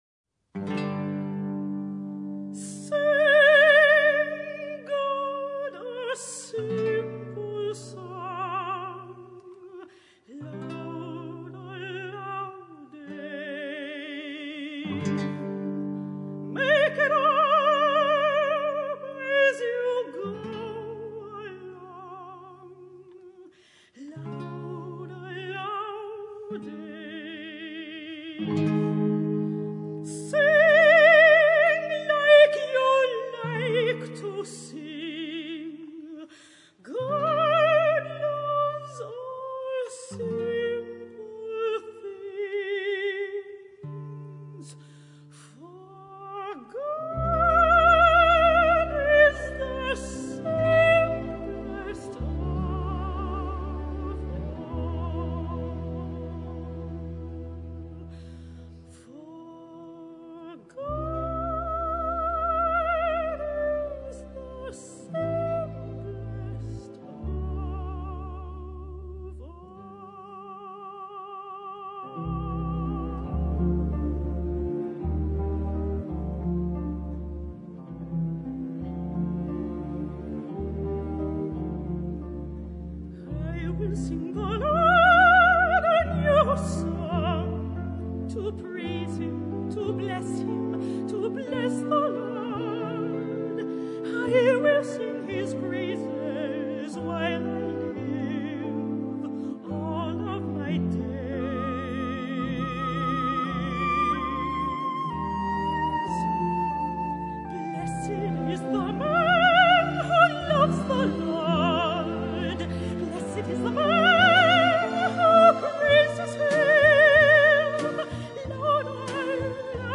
Classicas